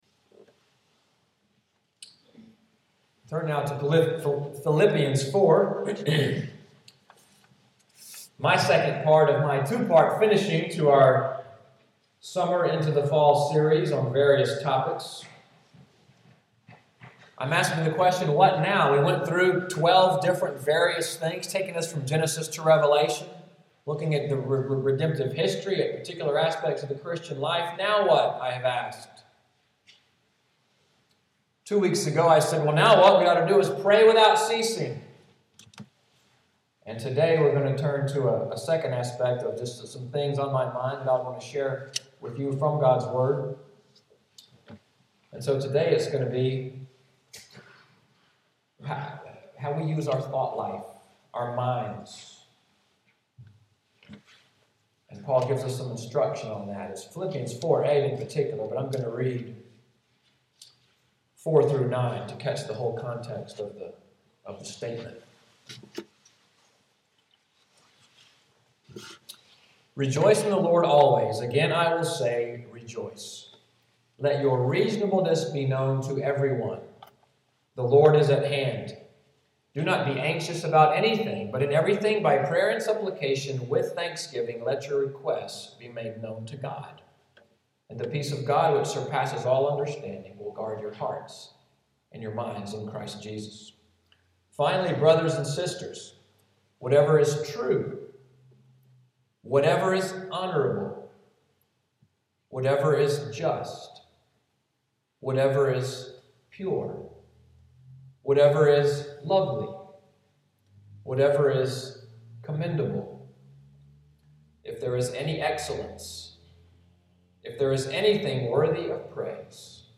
Audio of the sermon, “Now What?” [Part II: The Battle for the Mind: Think…], preached Oct. 13, 2013.